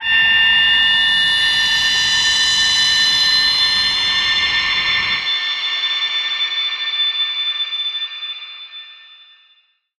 G_Crystal-A8-mf.wav